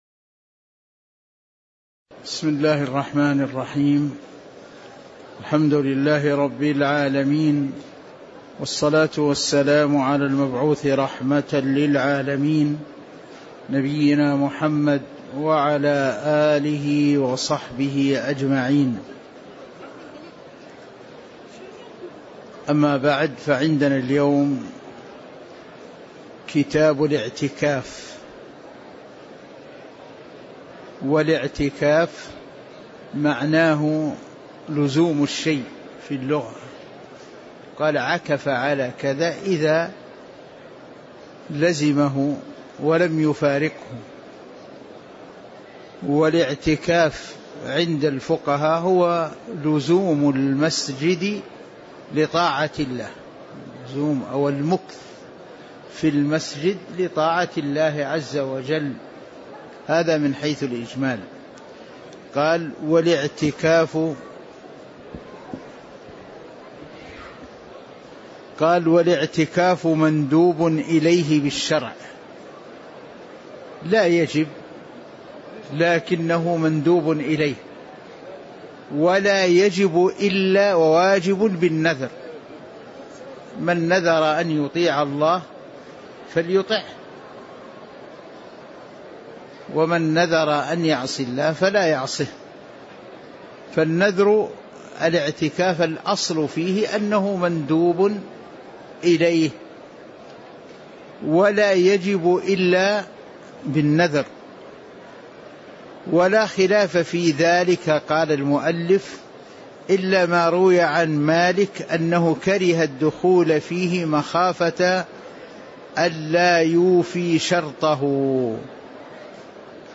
تاريخ النشر ١٩ رجب ١٤٤٦ هـ المكان: المسجد النبوي الشيخ